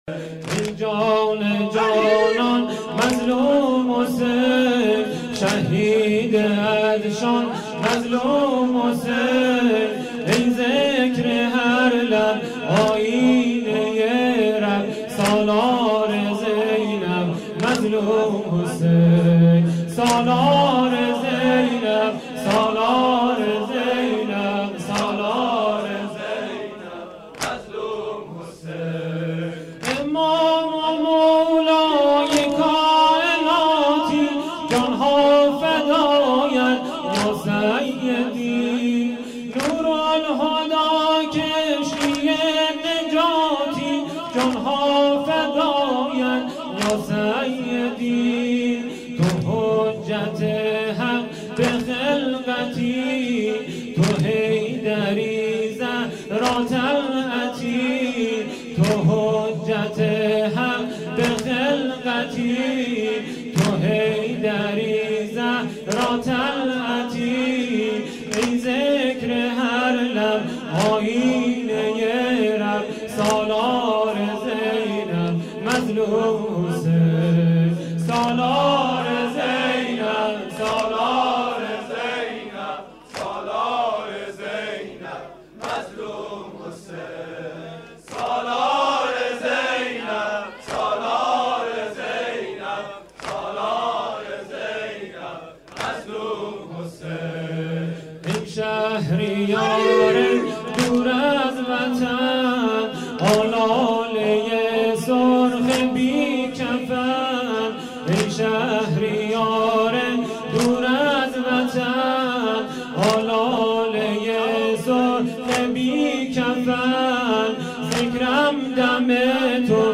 واحد تند
شب هفتم محرم 1397